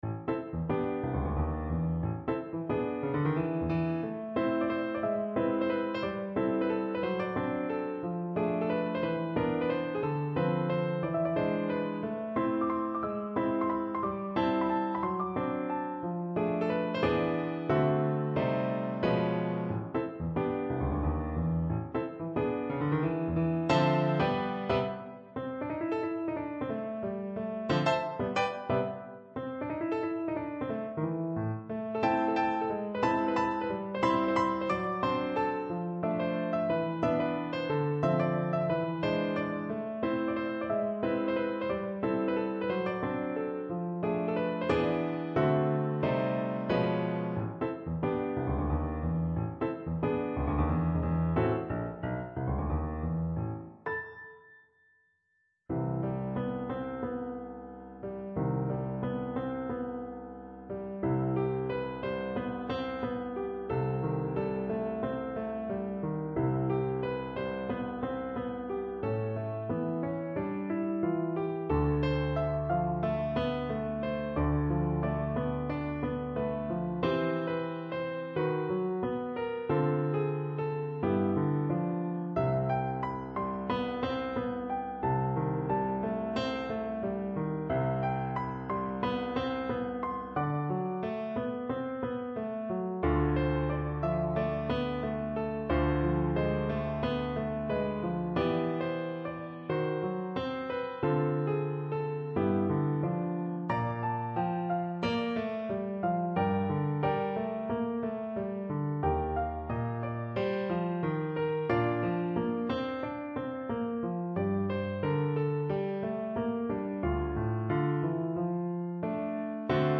for piano solo
Three pieces for piano solo with the flavour of New York.